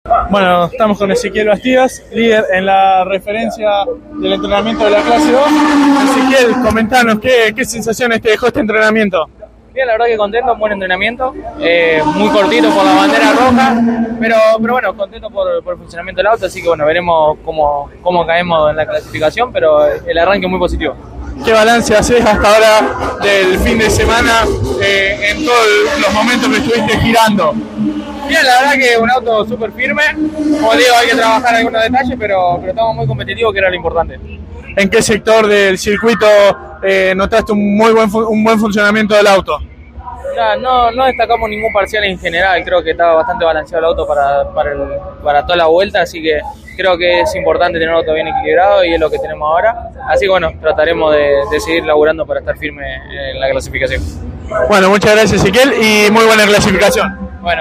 en diálogo con CÓRDOBA COMPETICIÓN